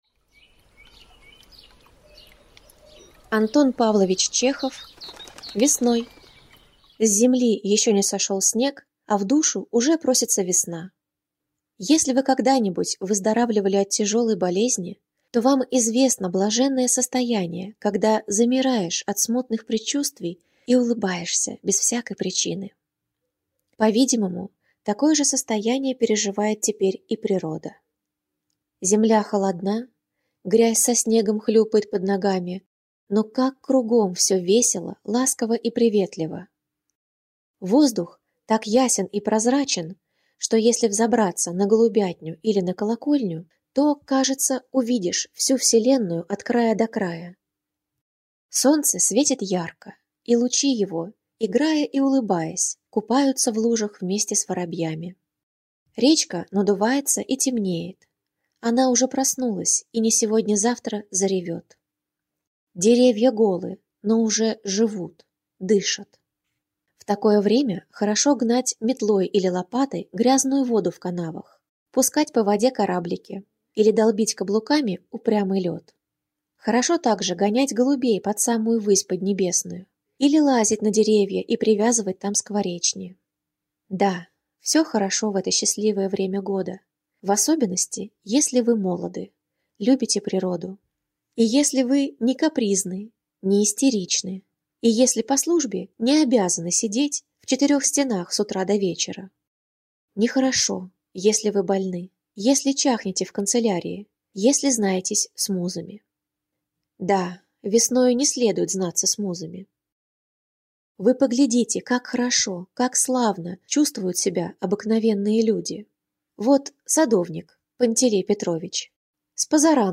В рамках нашего проекта "Волонтёры читают" мы подготовили аудиоверсию и этого рассказа!Почувствуйте, как оживают чеховские персонажи.